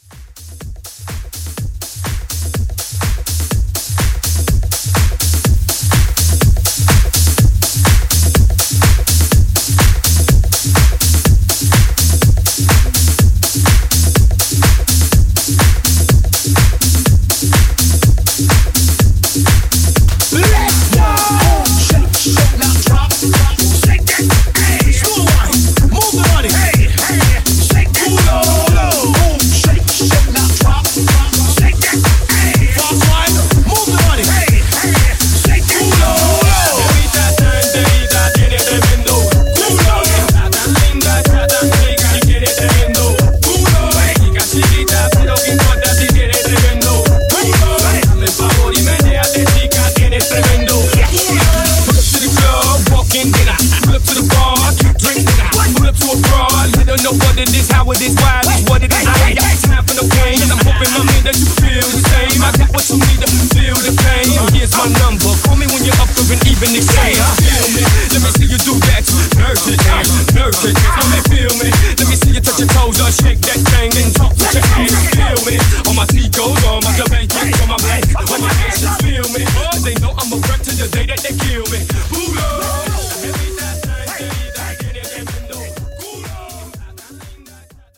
Genres: DANCE , MASHUPS Version: Clean BPM: 124 Time